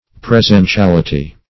Search Result for " presentiality" : The Collaborative International Dictionary of English v.0.48: Presentiality \Pre*sen`ti*al"i*ty\, n. State of being actually present.